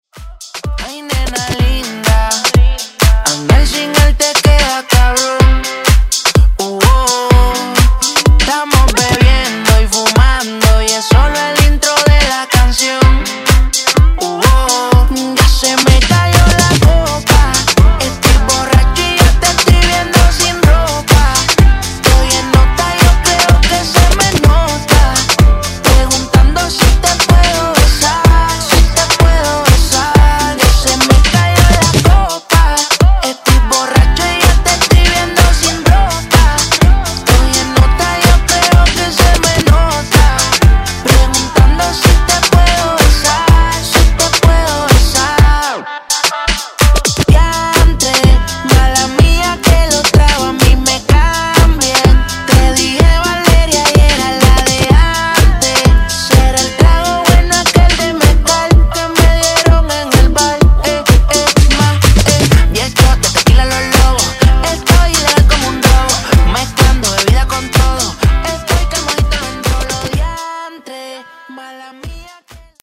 Genre: DANCE
Clean BPM: 126 Time